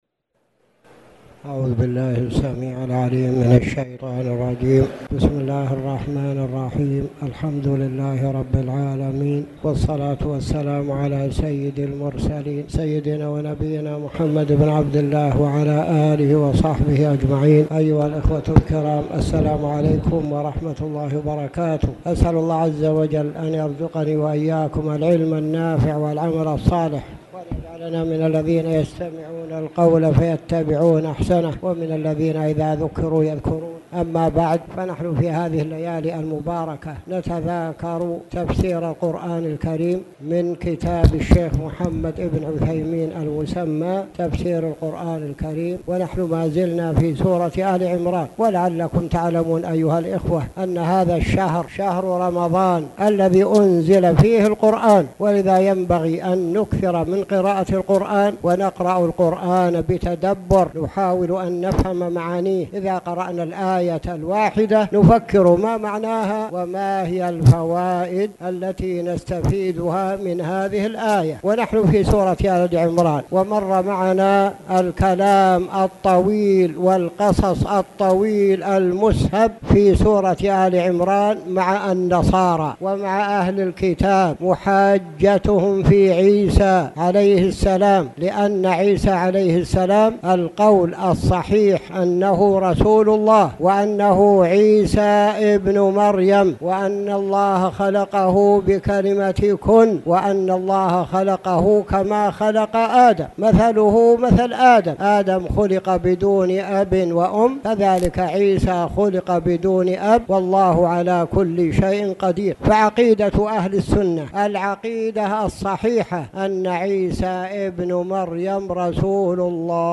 تاريخ النشر ٣ رمضان ١٤٣٨ هـ المكان: المسجد الحرام الشيخ